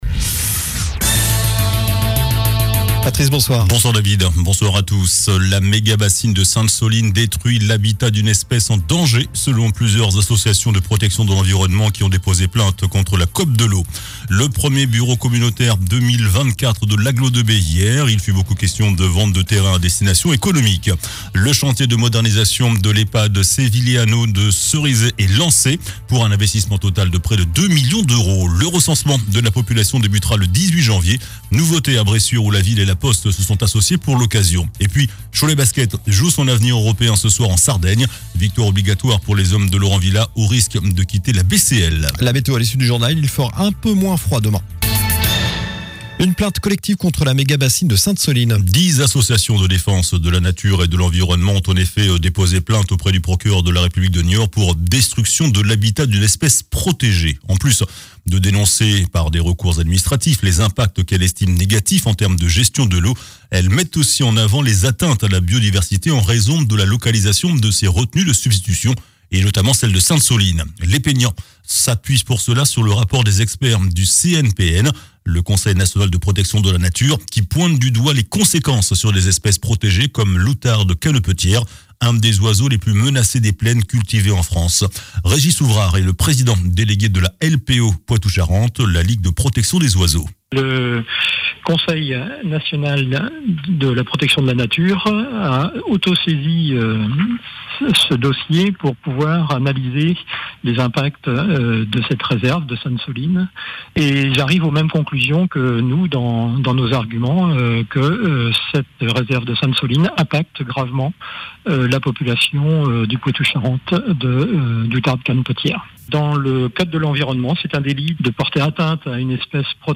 JOURNAL DU MERCREDI 10 JANVIER ( SOIR )